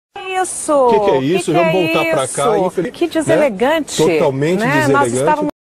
Áudio do famoso Que Deselegante, soltado por Sandra Annenberg e Evaristo Costa durante programa Jornal Hoje